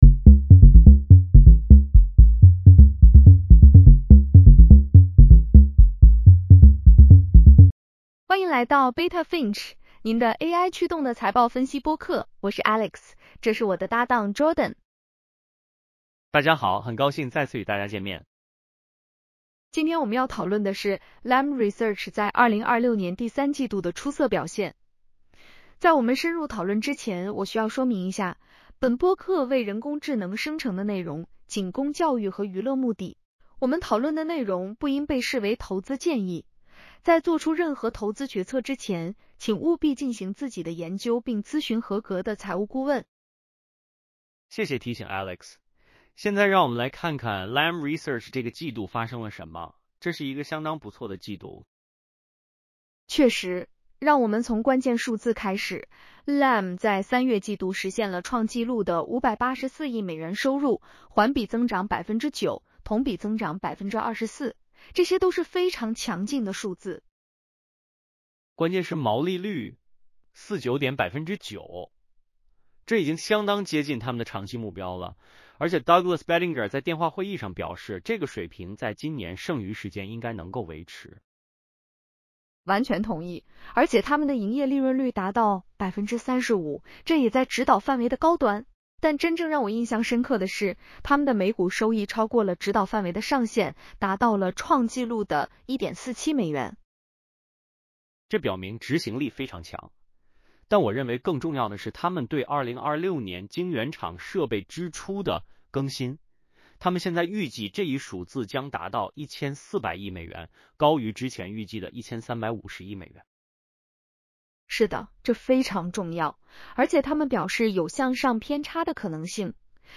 欢迎来到Beta Finch，您的AI驱动的财报分析播客。